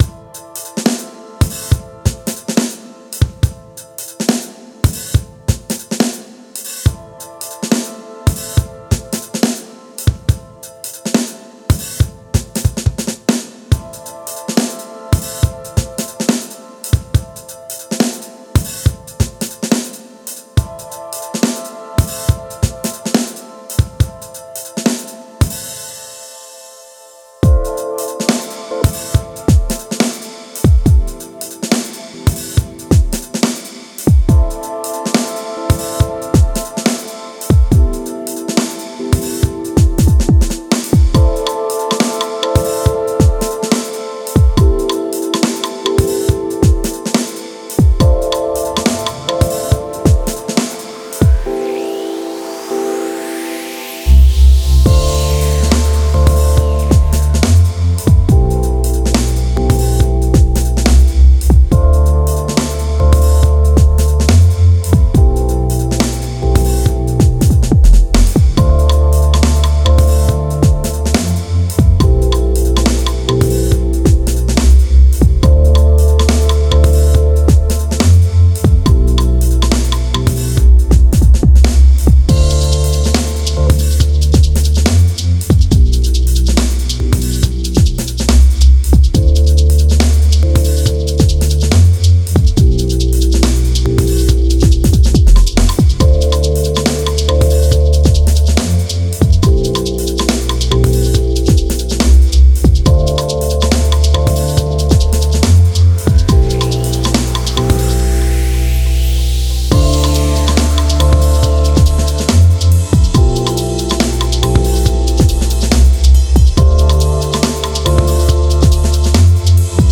Genre: Deep Dubstep, Dub, Electronic.